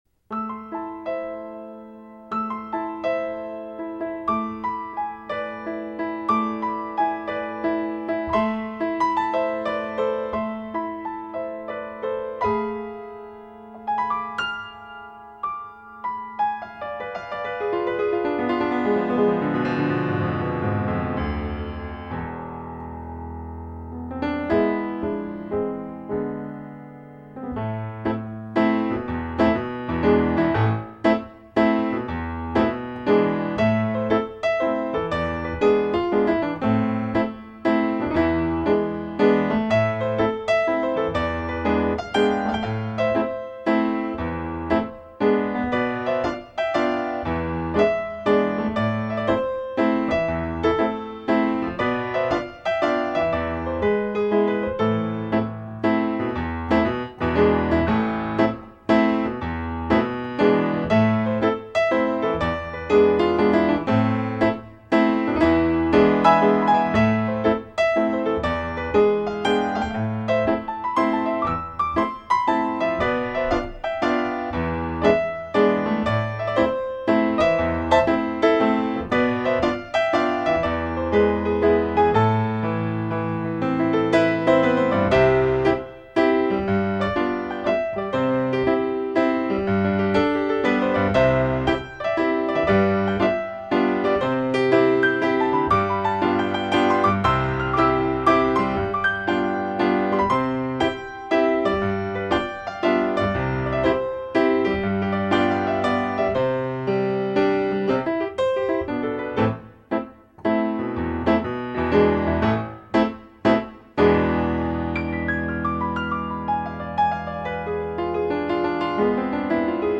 ヒーリングＣＤ